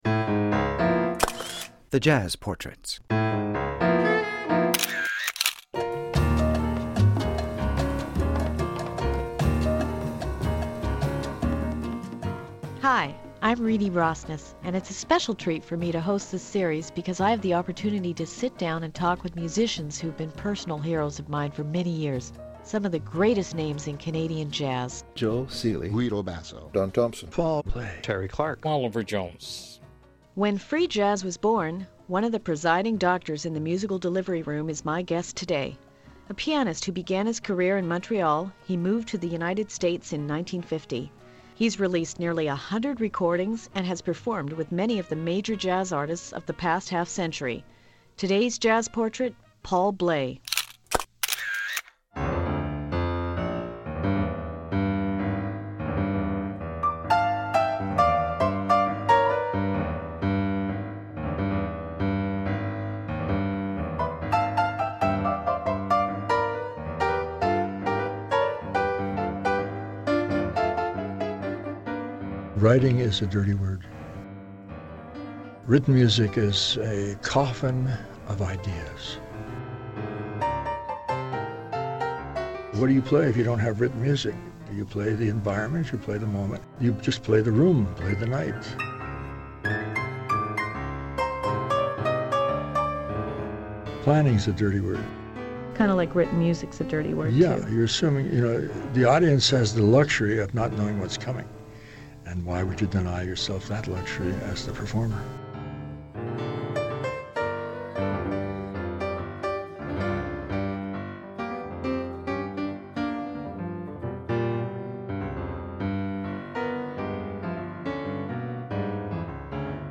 The internationally renowned alto saxophonist and composer is a two-time Juno-award-winner.